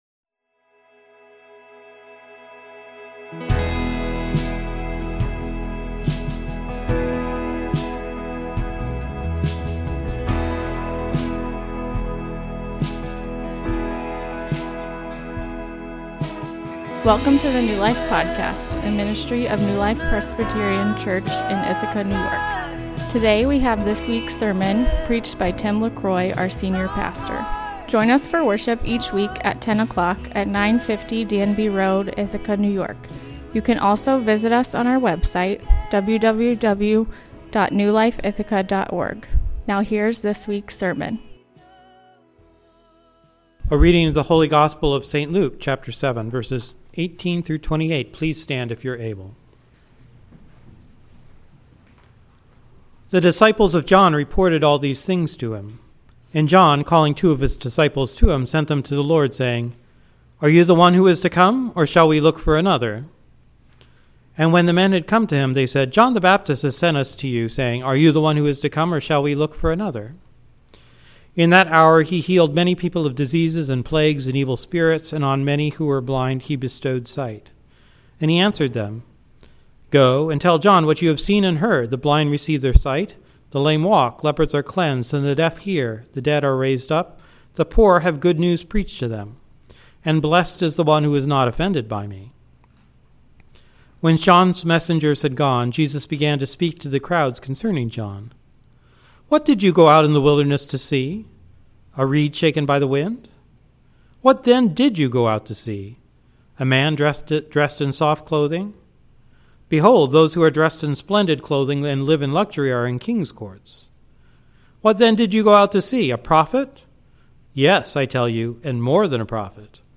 Sermon outline: 1. Jesus says doubt is understandable 2. Jesus dignifies the doubter 3. Jesus defends the doubter 4. Jesus (gently) admonishes the doubter